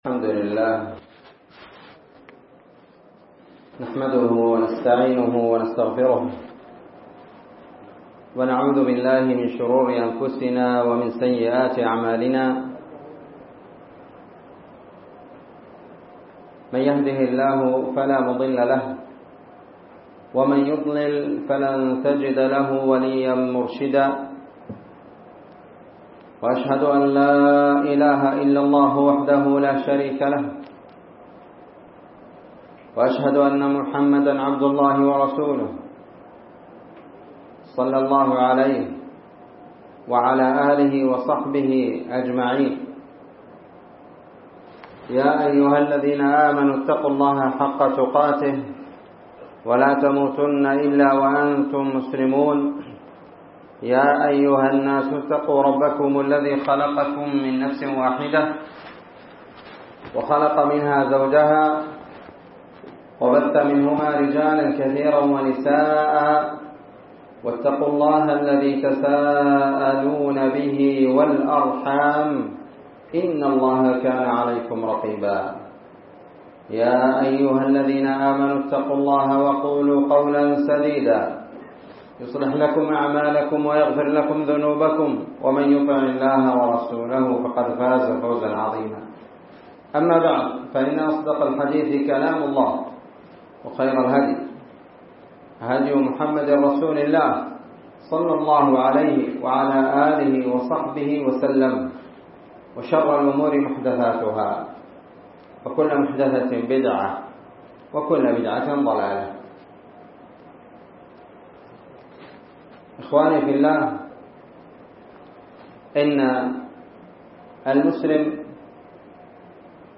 محاضرة بعنوان تفسير سورة الإخلاص ٤ جمادى الآخرة ١٤٤٤